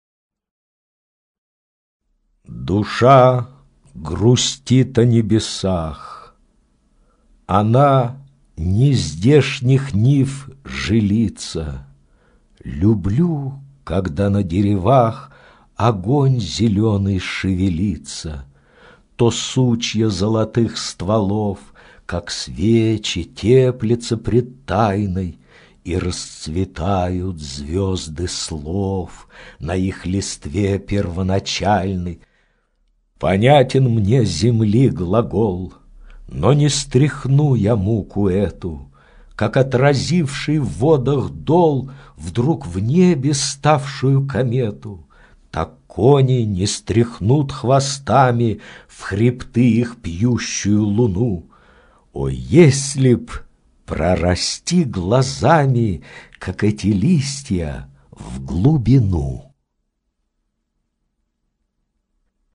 Прослушивание аудиозаписи стихотворения «Душа грустит о небесах...» с сайта «Старое радио».